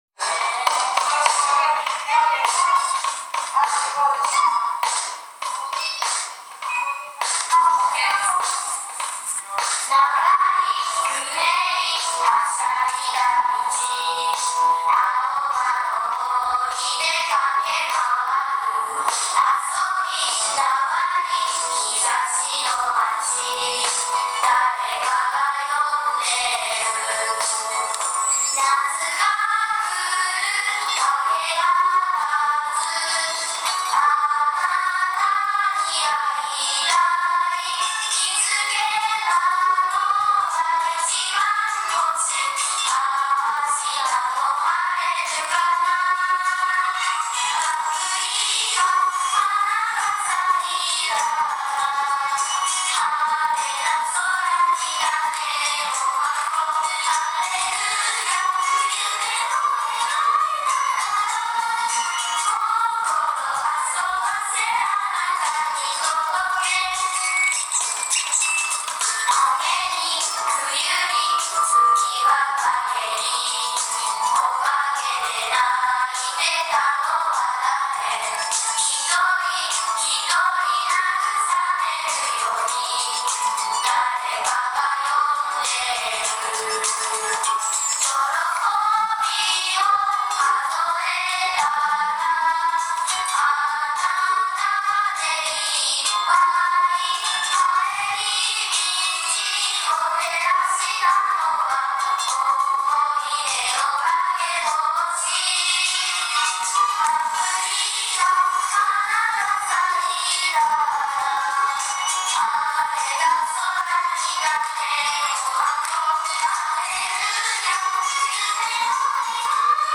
3月1日(金)、5年生が中心になって企画運営をして｢6年生を送る会｣を行いました。